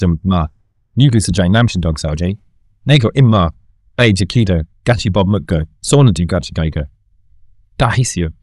먼저 MetaVoice 의 최익현씨 성대모사를 들어보자
지금까지 HuggingFace 극단의 외국인 배우의 한국영화 명대사 열연을 보셨습니다.